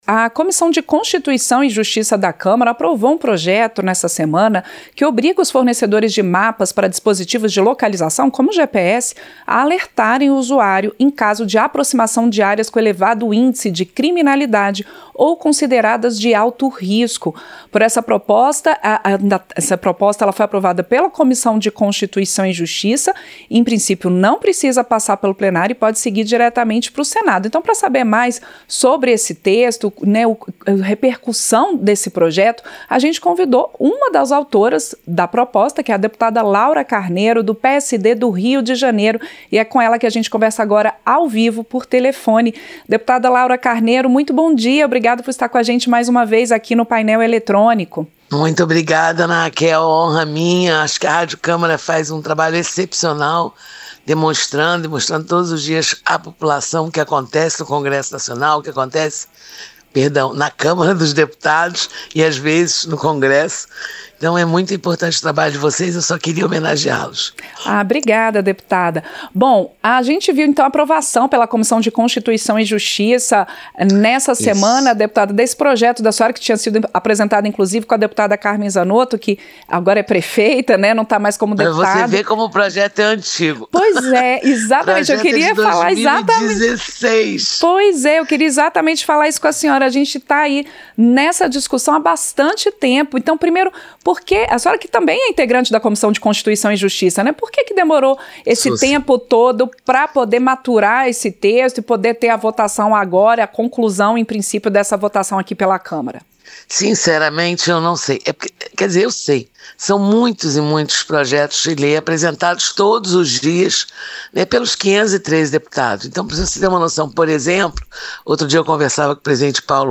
• Entrevista - Dep. Laura Carneiro (PSD/RJ)
Programa ao vivo com reportagens, entrevistas sobre temas relacionados à Câmara dos Deputados, e o que vai ser destaque durante a semana.